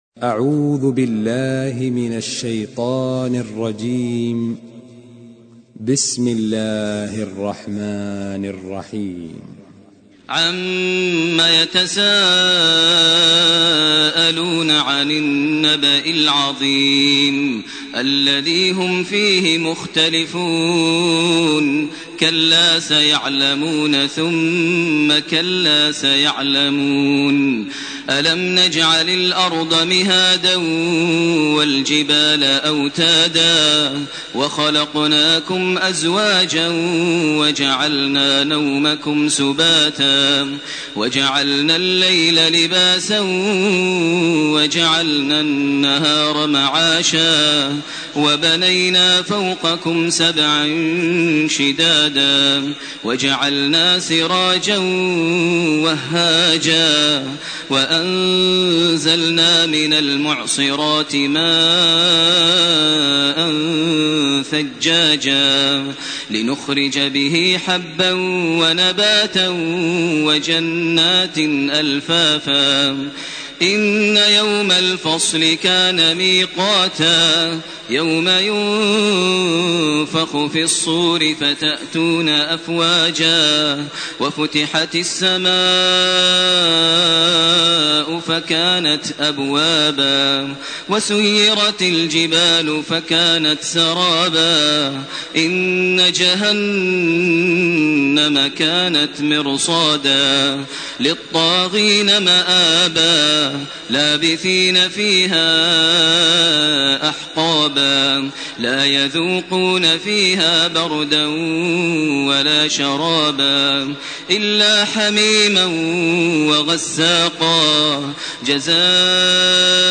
النبأ _النازعات_عبس _التكوير _الانفطار _المطففين _ الانشقاق _ البروج _ الطارق _الغاشية > تراويح ١٤٢٨ > التراويح - تلاوات ماهر المعيقلي